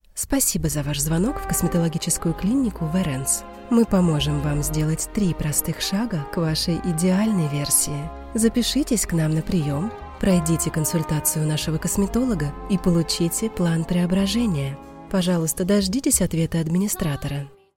Жен, Автоответчик/Средний
Приятный и нежный голос с хорошей дикцией.